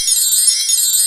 Windbells.wav